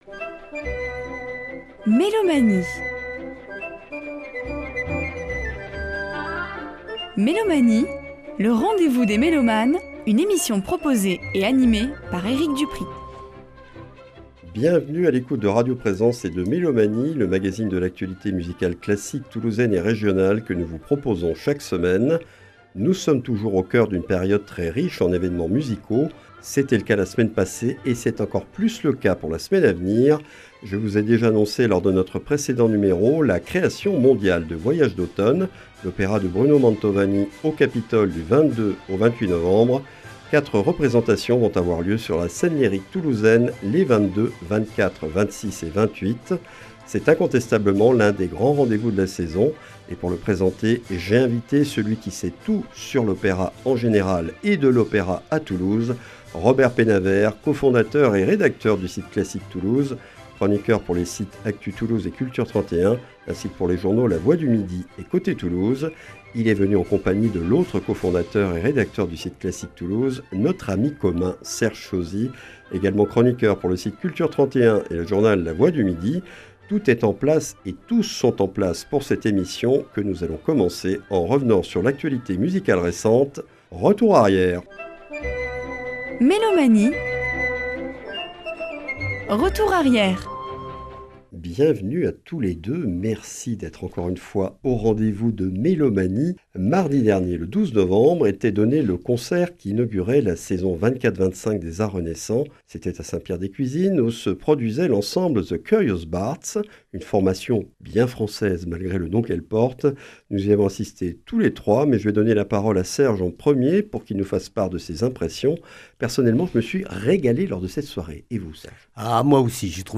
Mélomanie(s) et ses chroniqueurs présentent l’actualité musicale classique de notre région.